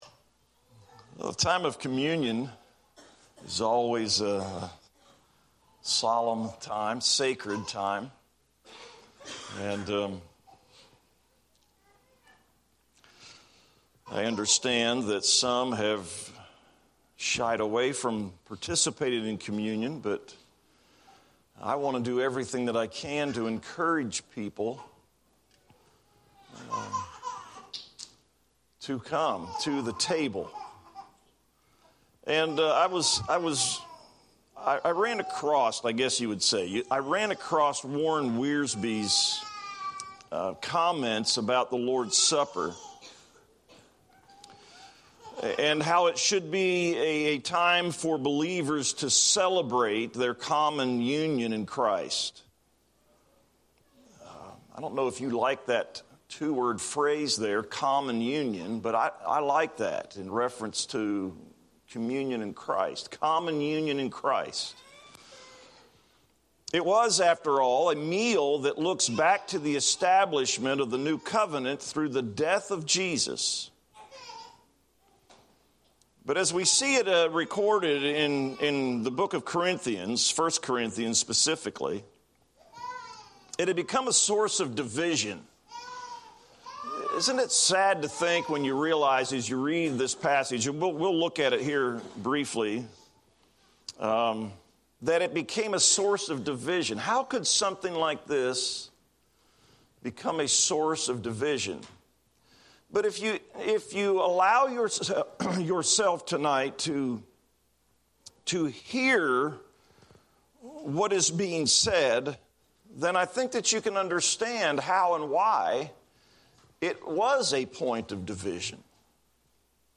devotional on the Lord’s Supper based on comments from Warren Wiersbe